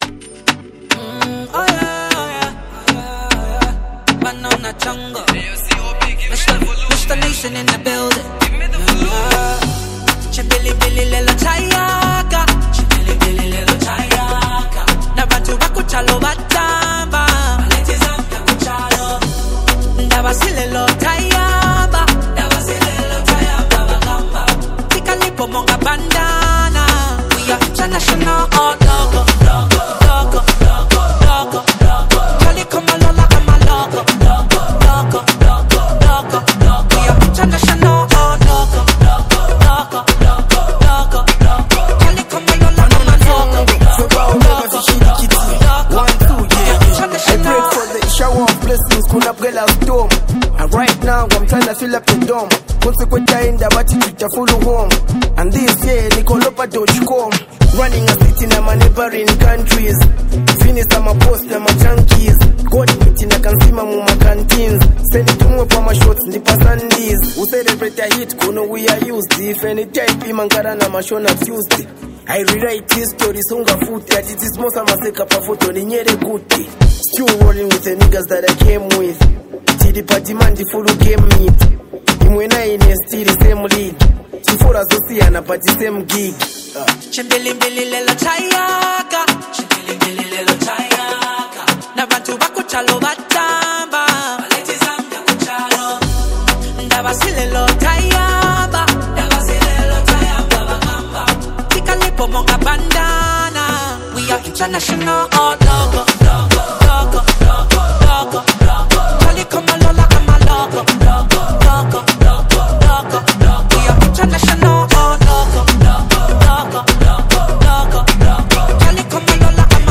The production is sleek and polished